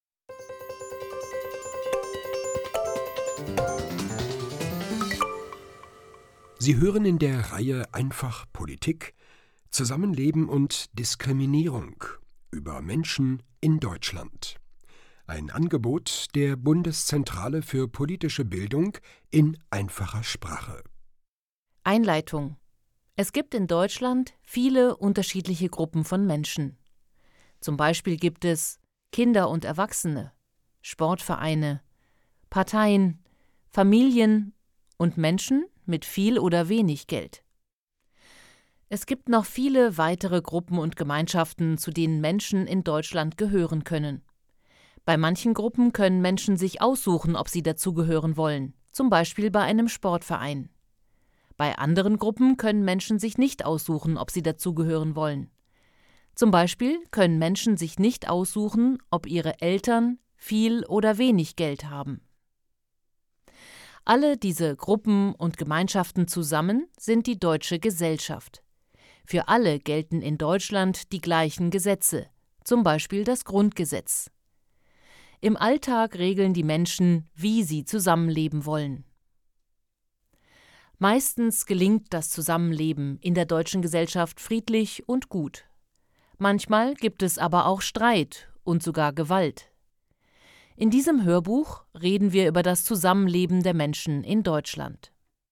Einleitung: Zusammenleben in Deutschland Hörbuch: „einfach POLITIK: Zusammenleben und Diskriminierung“
• Produktion: Studio Hannover